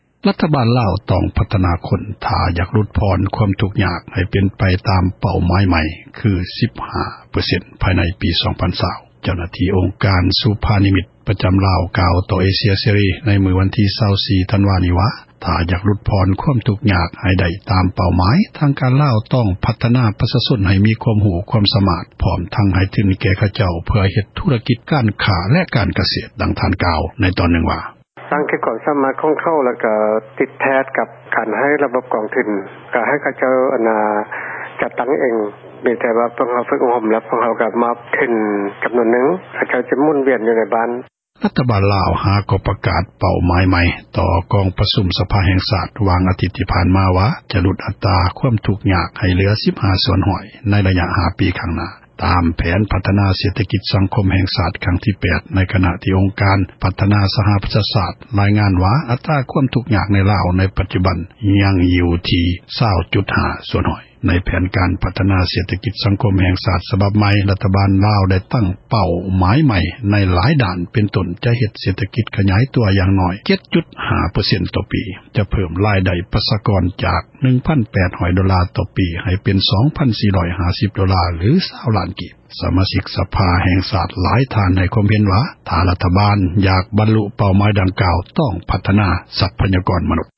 ເປົ້າໝາຍຫຼຸດຜ່ອນຄວາມ ທຸກຍາກ — ຂ່າວລາວ ວິທຍຸເອເຊັຽເສຣີ ພາສາລາວ